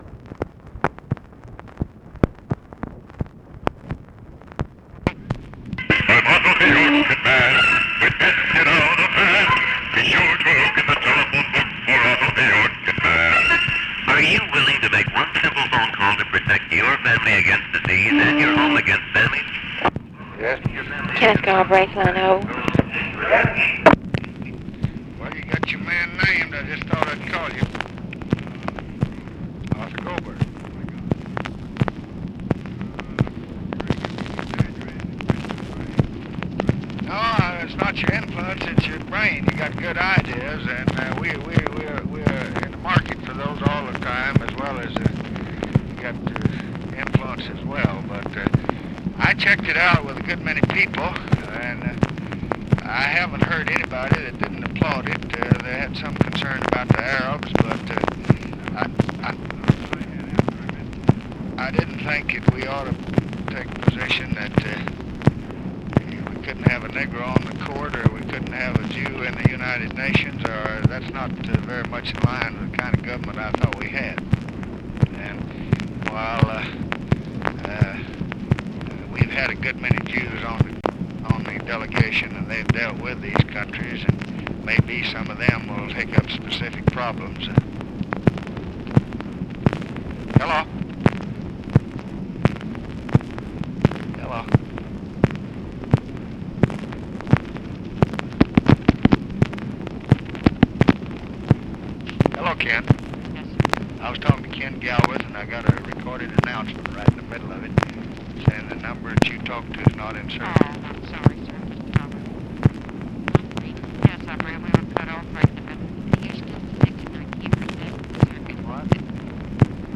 Conversation with JOHN KENNETH GALBRAITH, OFFICE NOISE, OFFICE CONVERSATION and TELEPHONE OPERATORS, July 20, 1965